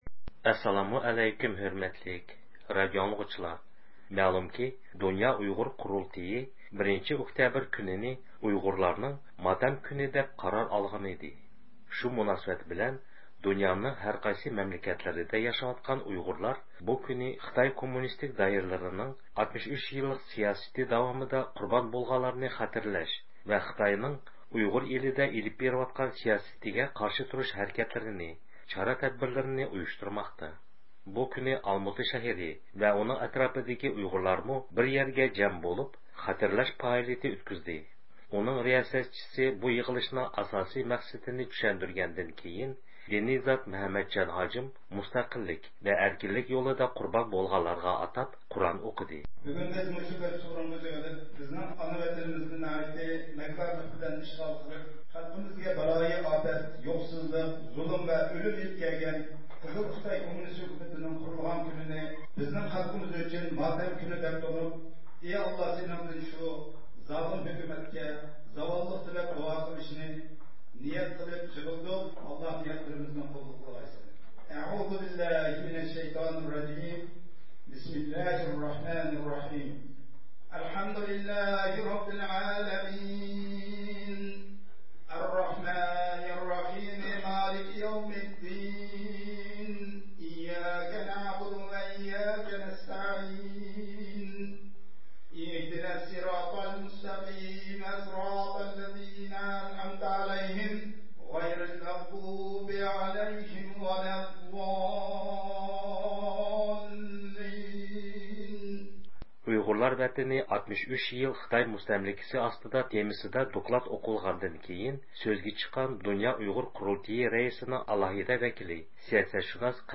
بۇ كۈنى ئالماتا شەھىرى ۋە ئۇنىڭ ئەتراپىدىكى ئۇيغۇرلارمۇ بىر يەرگە جەم بولۇپ، خاتىرىلەش پائالىيىتى ئۆتكۈزدى.